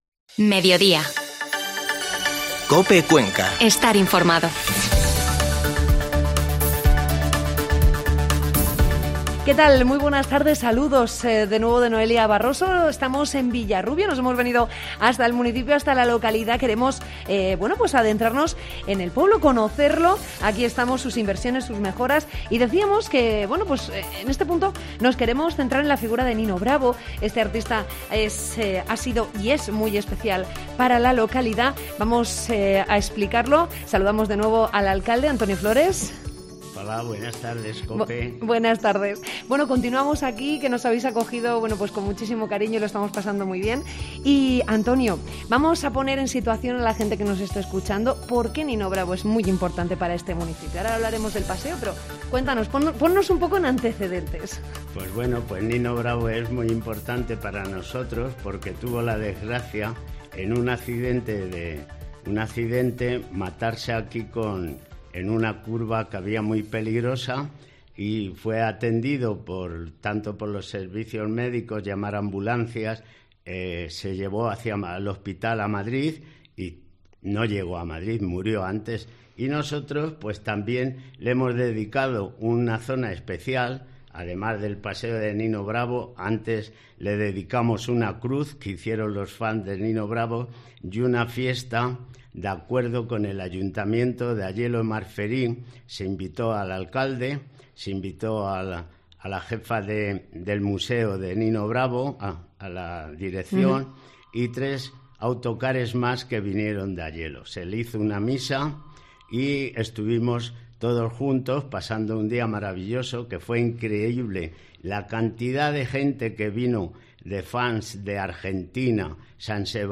Segunda parte de la entrevista con el alcalde de Villarrubio, Antonio Flores